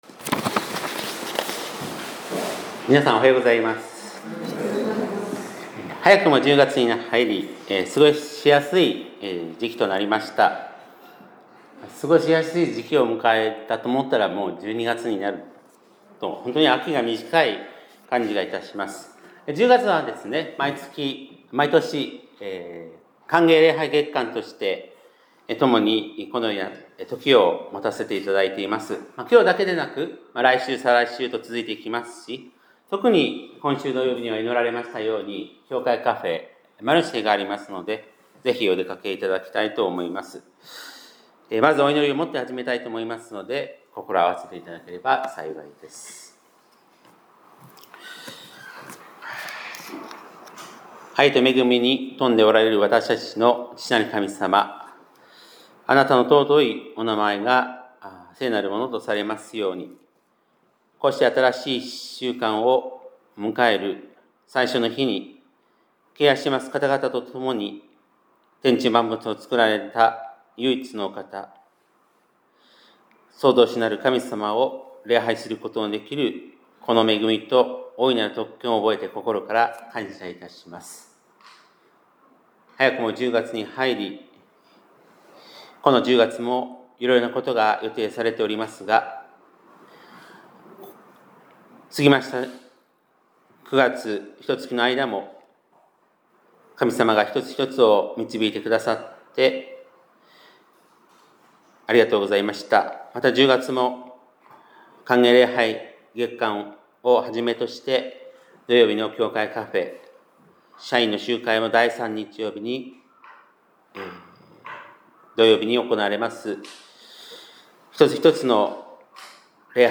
2025年10月5日（日）礼拝メッセージ - 香川県高松市のキリスト教会
2025年10月5日（日）礼拝メッセージ